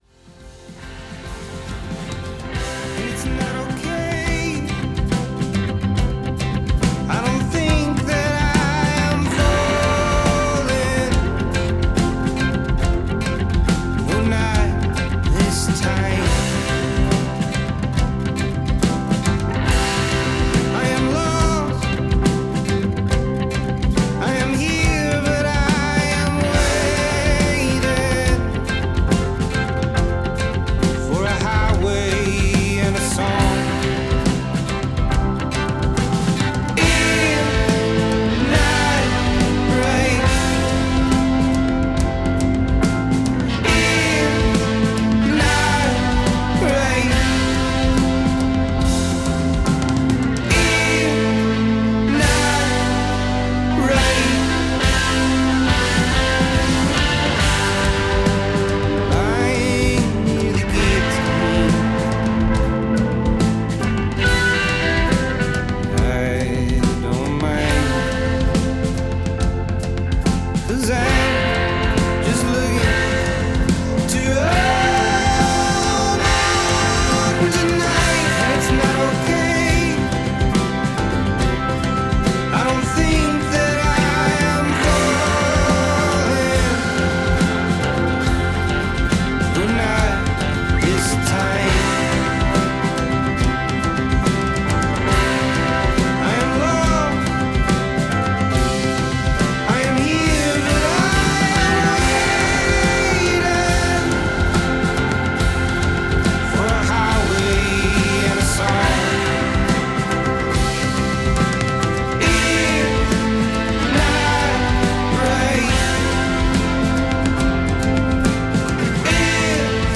Americana, Folk
dramatic indie rock tunes